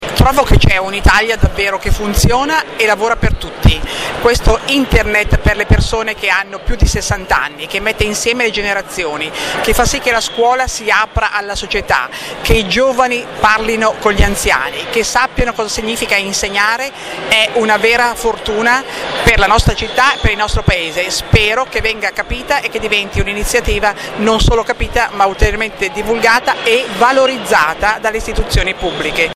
Oggi presso la galleria Alberto Sordi di Roma la senatrice Mariapia Garavaglia in visita al Corner degli Angeli digitali si è congra...